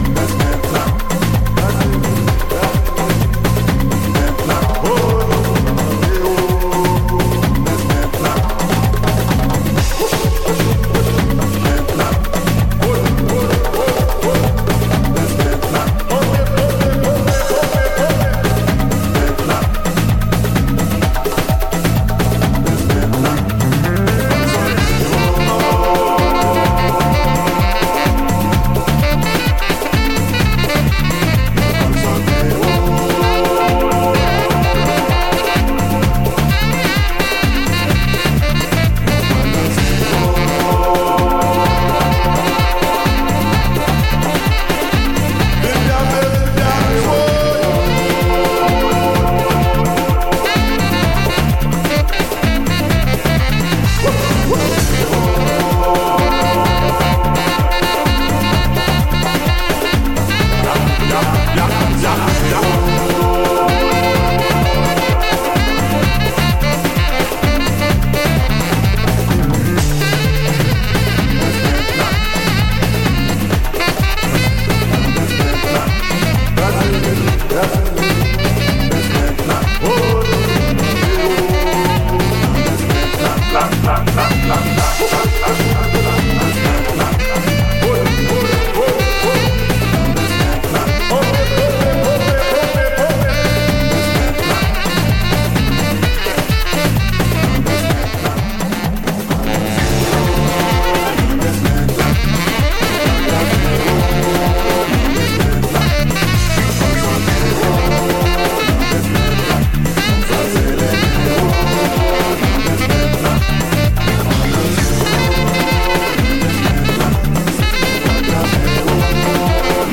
edits and overdubs of African vibrational sounds and rhythms